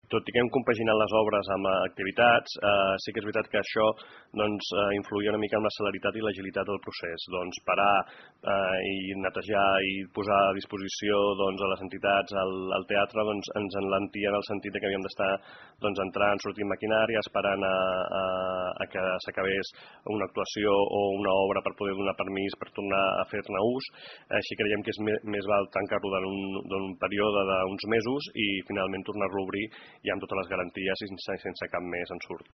Ho explica el regidor d’urbanisme, Josep Rueda.